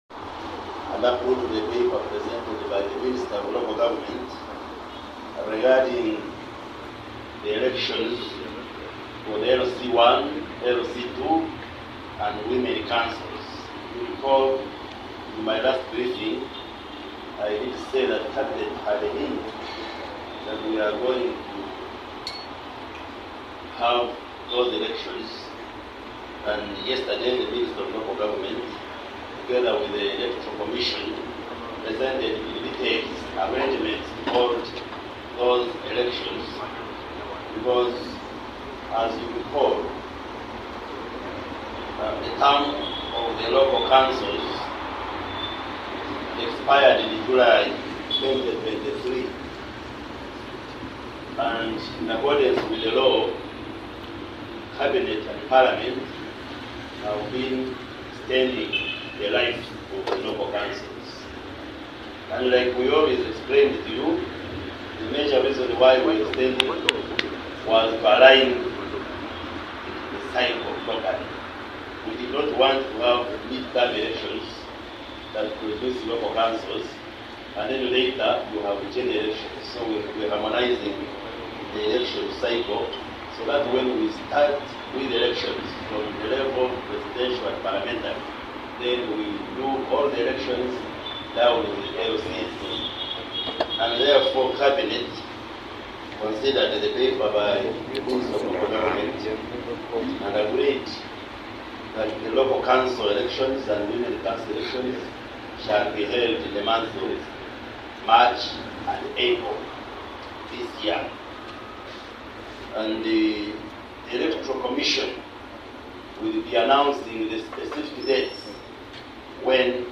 Addressing journalists at the Uganda Media Centre in Kampala on Tuesday, Baryomunsi revealed that Cabinet, at its Monday sitting, endorsed a paper presented by the Minister of Local Government regarding the long-awaited polls.